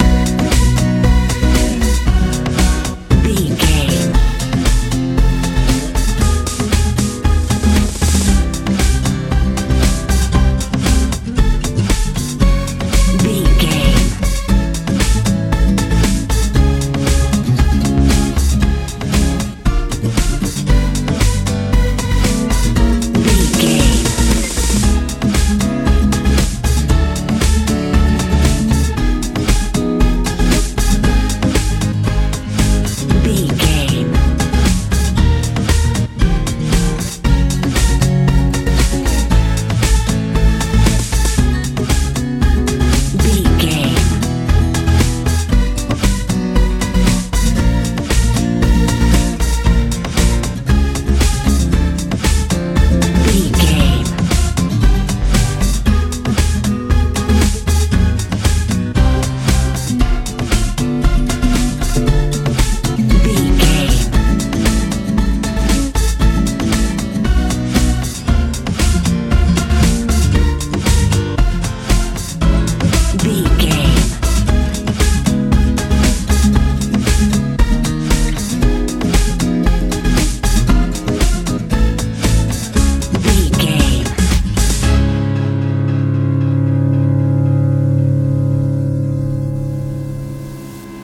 dance pop feel
Ionian/Major
groovy
hypnotic
bass guitar
drums
acoustic guitar
synthesiser
80s
90s